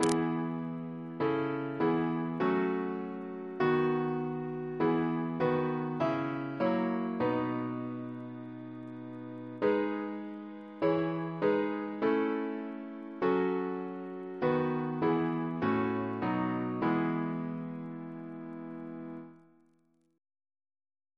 Double chant in E Composer: Henry G. Ley (1887-1962) Reference psalters: ACB: 388